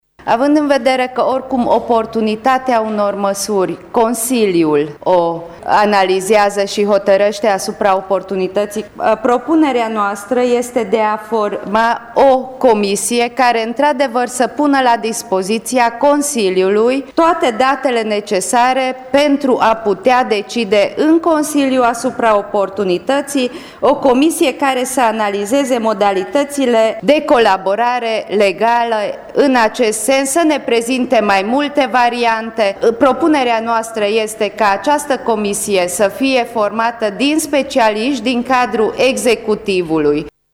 Consilierul UDMR, Koracsonyi Etel: